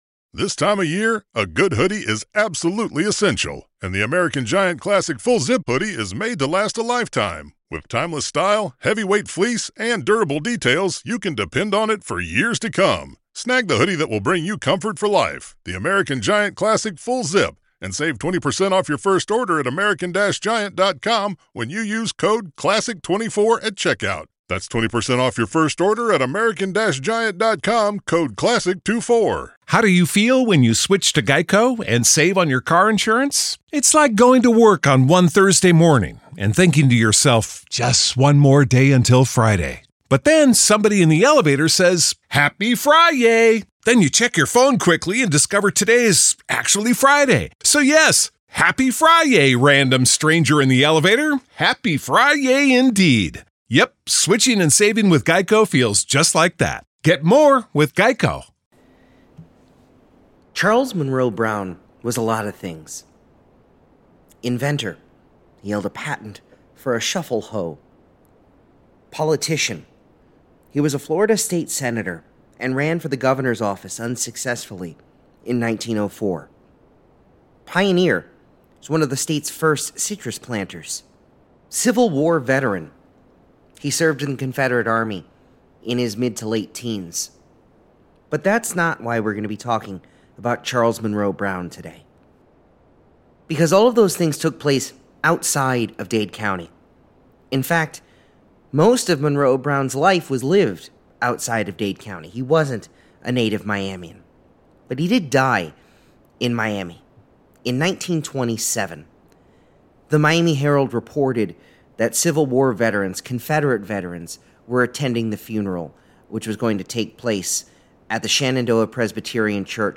Part of the Charles Munroe Brown House, in the middle of a move from Omni to Watson Island, collapsed on the MacArthur Causeway. We talk to Don Slesnick, who was then president of the Dade Heritage Trust, about the calamity.